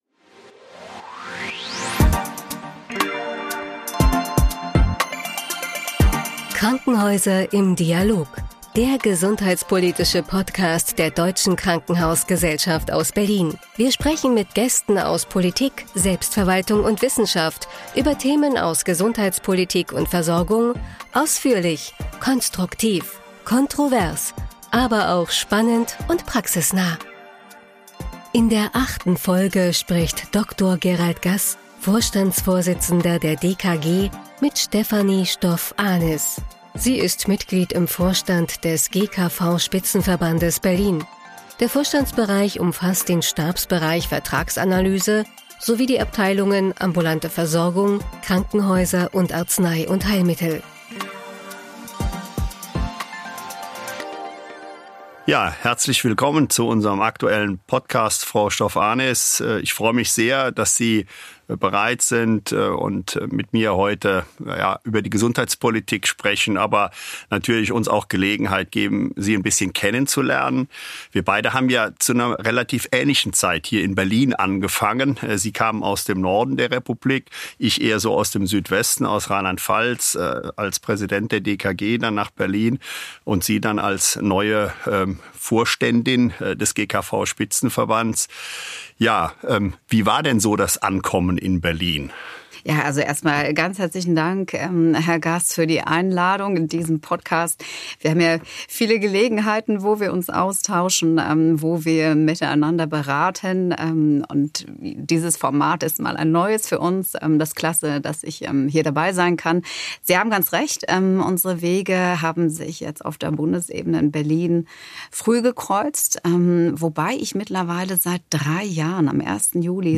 Das Gespräch, aufgezeichnet am 1.